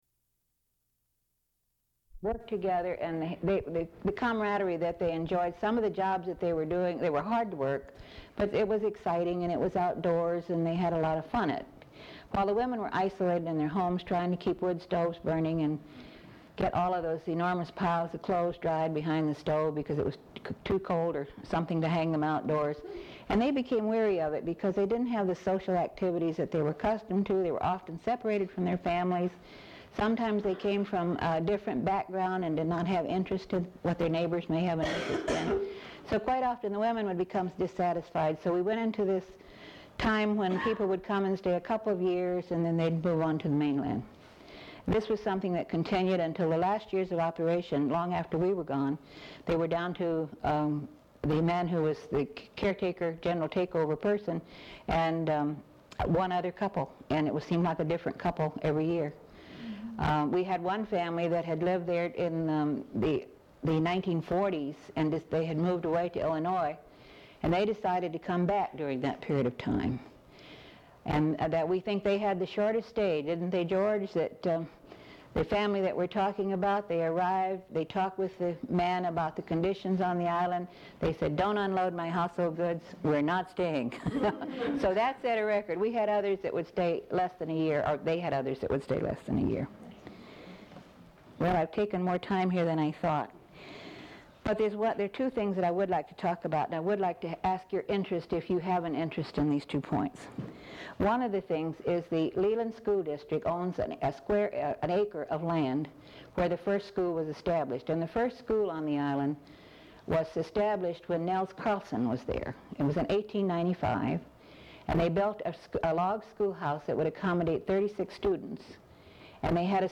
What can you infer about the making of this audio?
Scope & Content Tape, Audio Cassette.